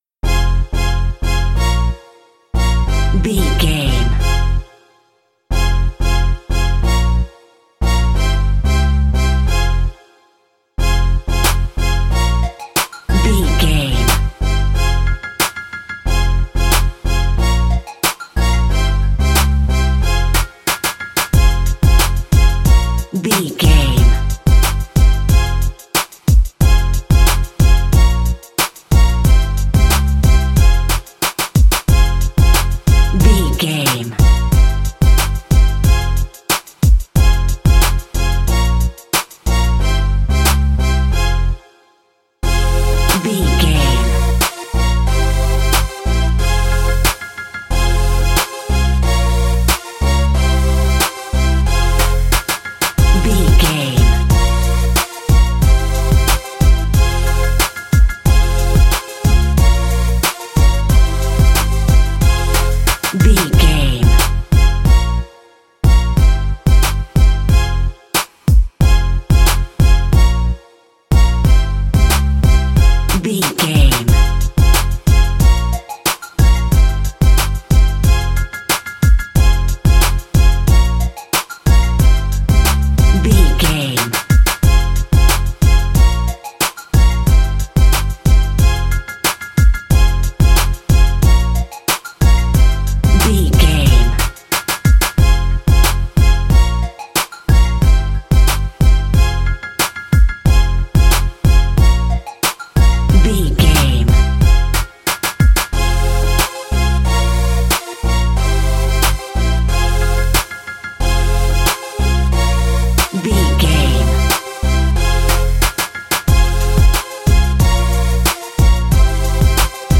Aeolian/Minor
piano
drum machine
synthesiser
funky